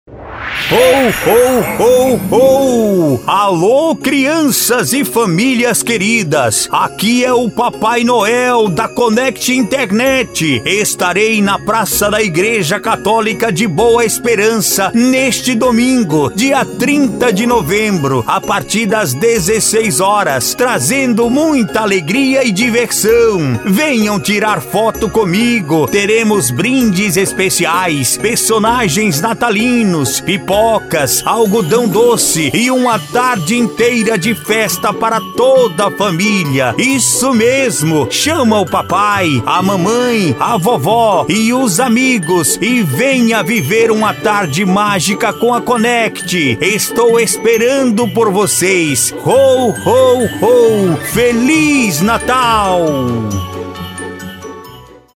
Formatos: Spot ComercialVinhetasChamada de FestaVT ComercialAberturasURAEspera Telefônica Áudio Visual Post em Áudio Gravação Política
Estilo(s): PadrãoImpacto Animada Varejo Caricata